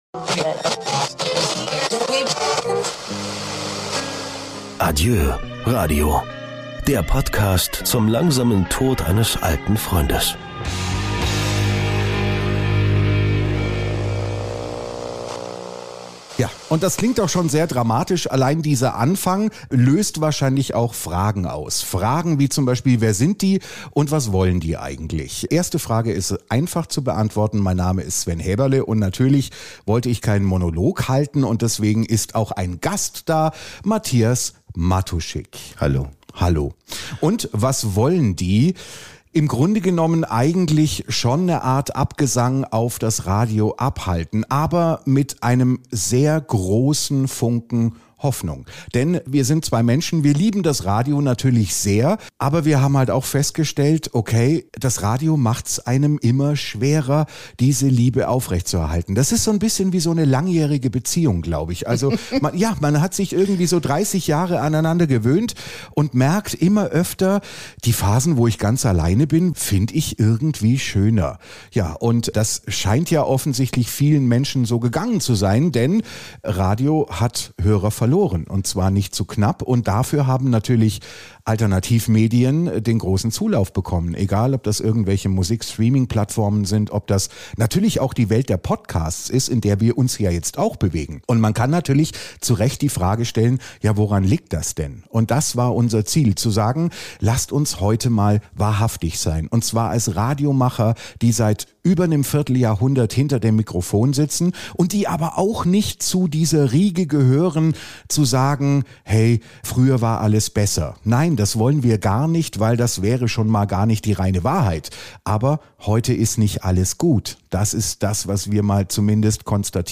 Zwei Radiomoderatoren, die das Radio lieben - aber irgendwann merkten, dass es immer schwerer wird, diese Liebe aufrecht zu erhalten. Höchste Zeit also für eine schonungslose Bestandsaufnahme!